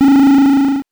powerup_41.wav